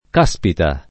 cazzica [k#ZZika] escl. — usata trivialm. nel ’500 per esprimere meraviglia; sopravvissuta nelle varianti eufem. capperi [k#pperi] e cappita [k#ppita], già del ’500, poi cattera [k#ttera] e più com. caspita [
k#Spita], solo dall’800